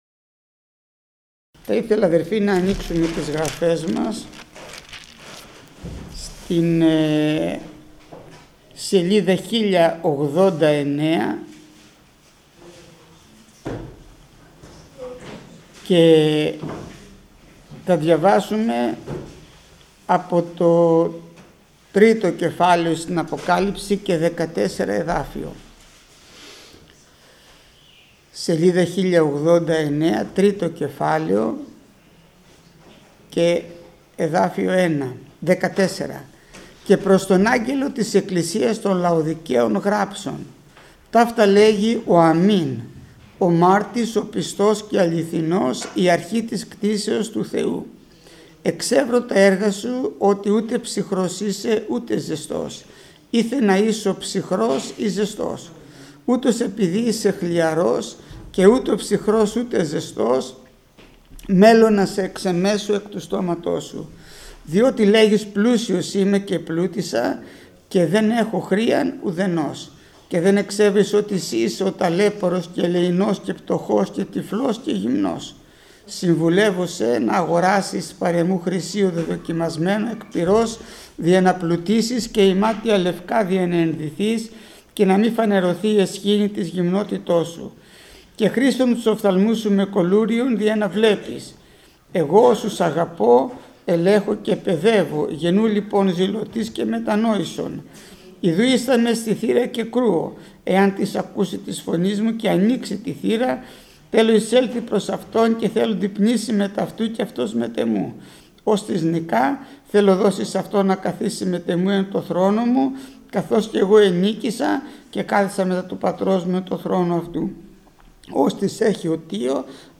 Μήνυμα πριν τη θεία κοινωνία